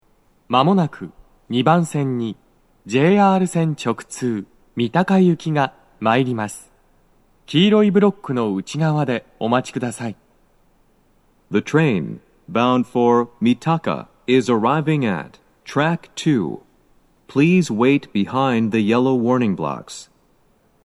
スピーカー種類 BOSE天井型
2番線の鳴動は、やや遅めです。
男声
接近放送3